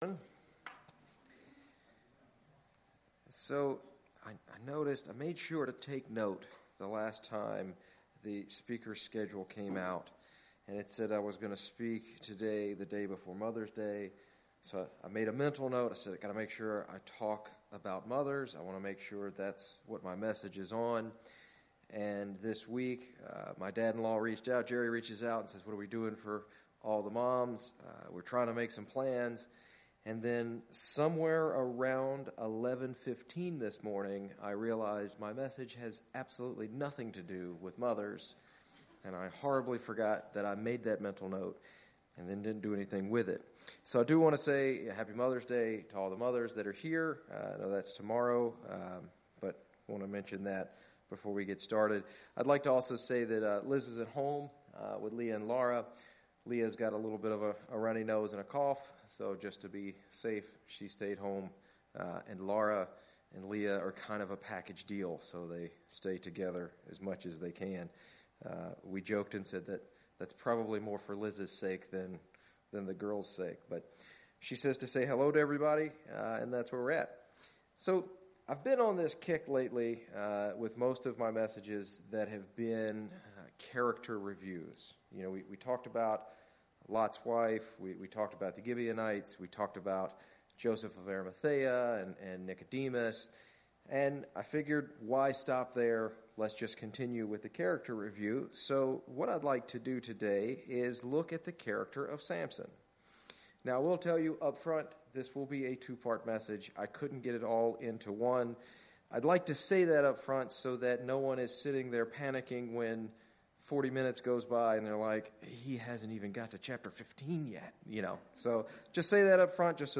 In this sermon we will look at the first half of Samson’s life.